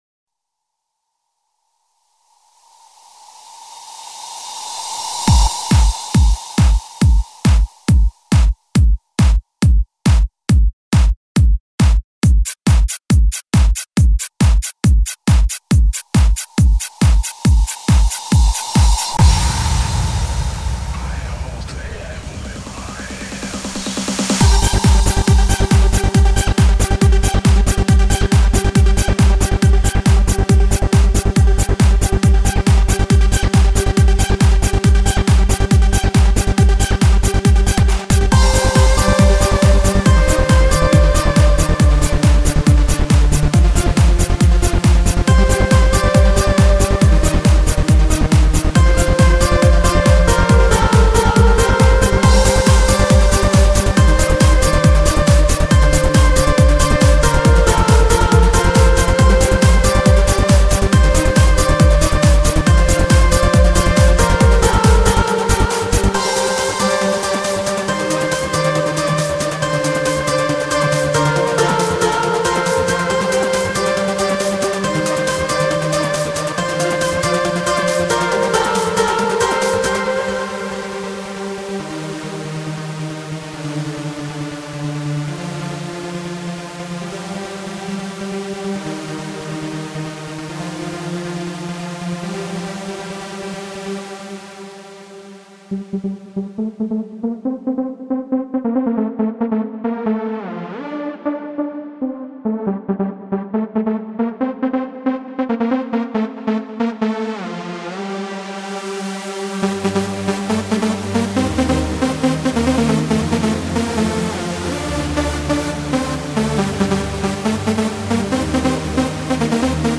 And of course i like melodic Trance.
Very interesting start. The intro is excellent.
The ending is long (typical for this genre).